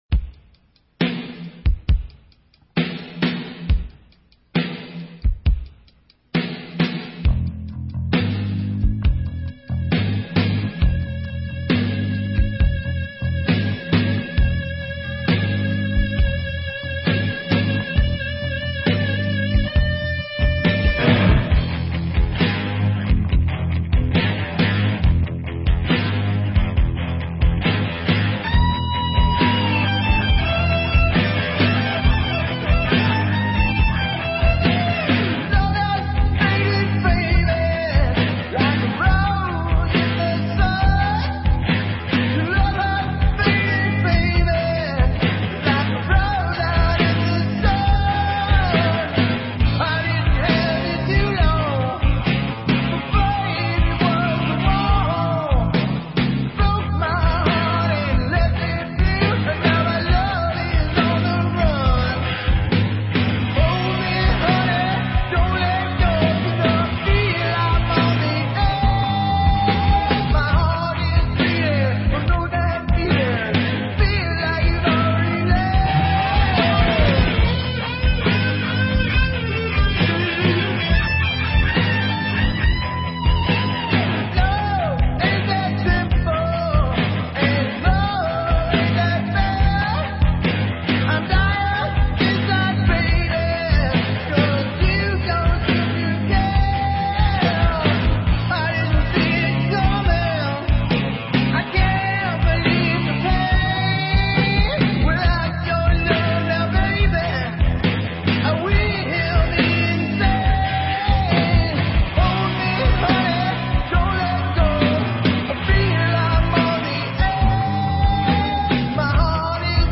Drums, Vocals
Bass
Guitar, Lead Vocals